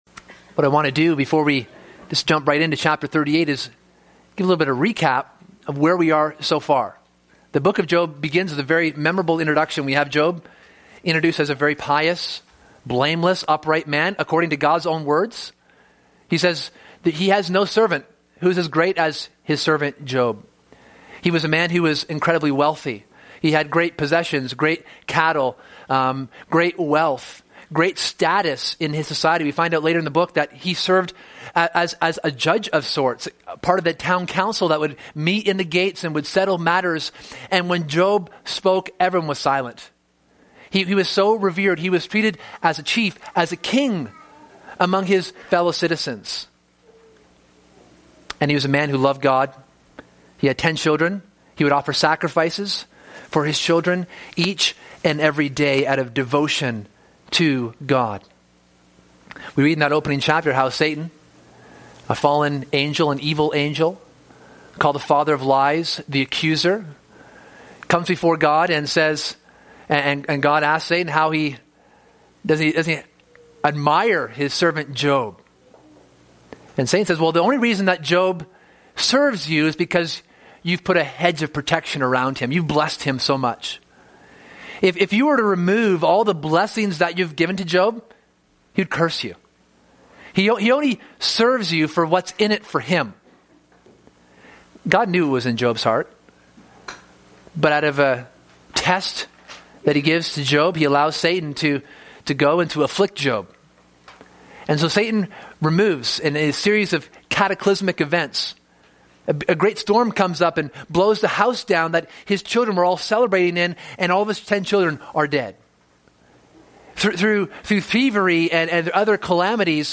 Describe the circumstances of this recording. October 18, 2020 ( Sunday AM ) Bible Text